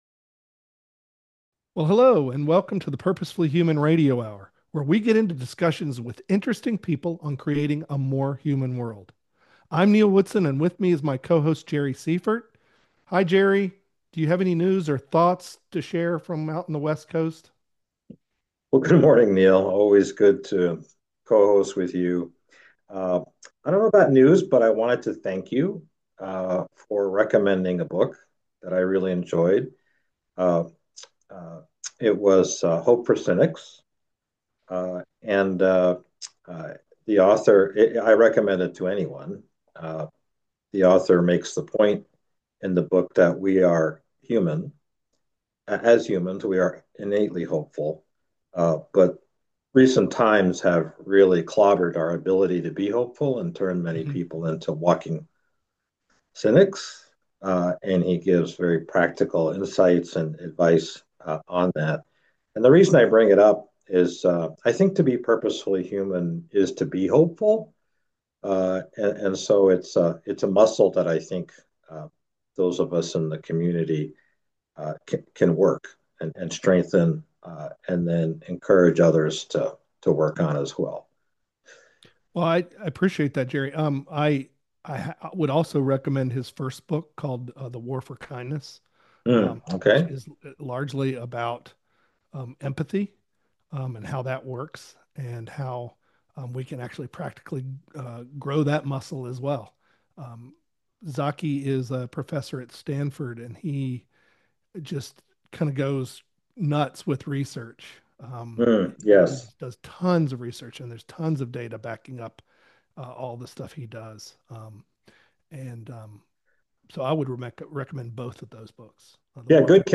In the PurposeFully Human Radio Hour we talk to interesting people about the need for more humanity in our world and get their ideas for moving in that direction.